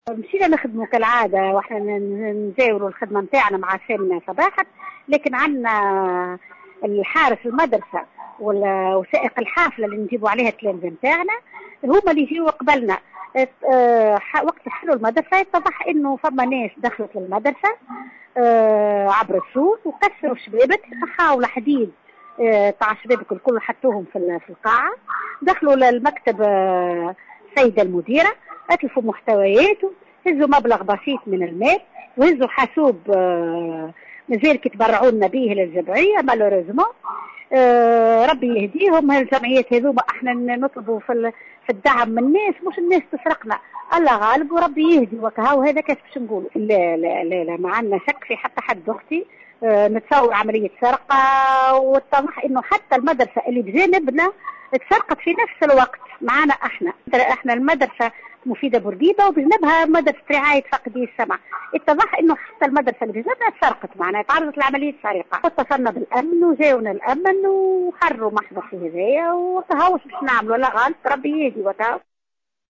في تصريح لمراسلة "الجوهرة أف أم"